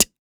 kits/OZ/Closed Hats/Hihat (Boi).wav at main
Hihat (Boi).wav